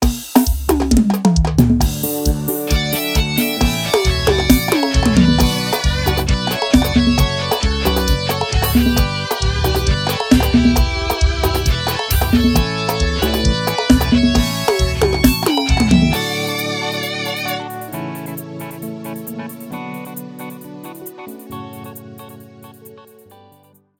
• Demonstrativo Axé: